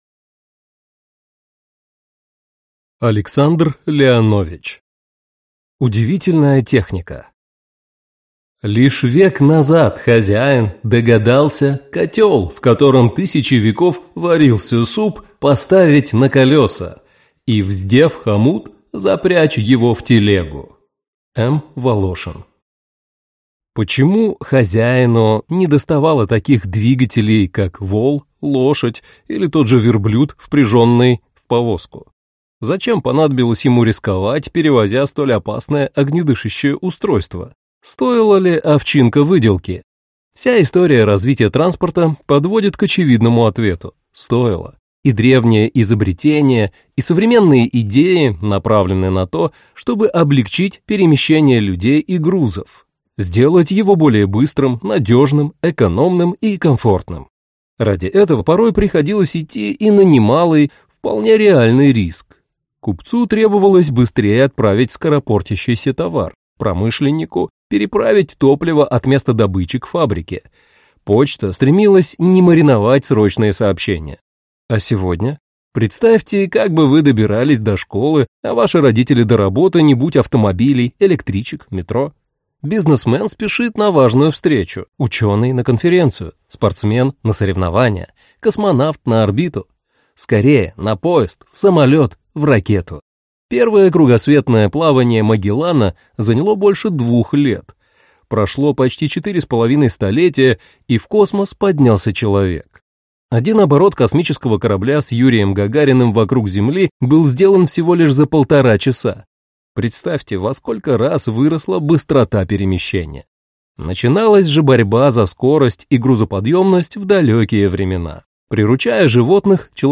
Аудиокнига Удивительная техника | Библиотека аудиокниг